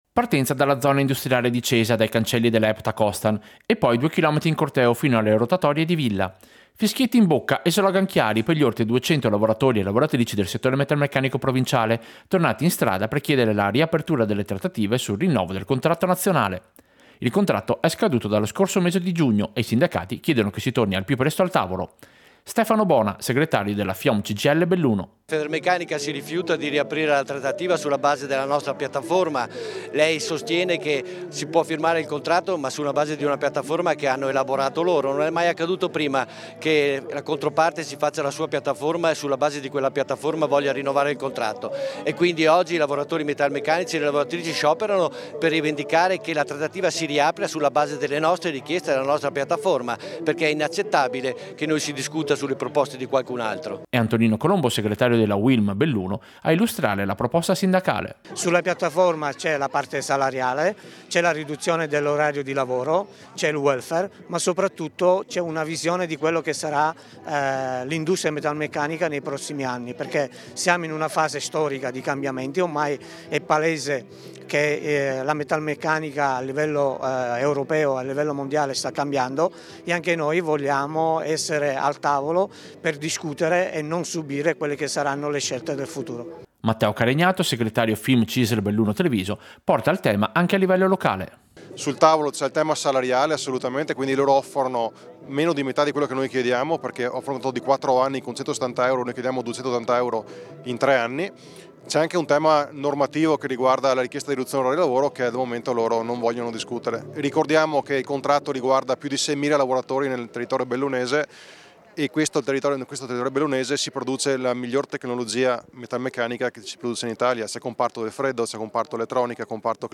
Servizio-Sciopero-metalmeccanici-Limana.mp3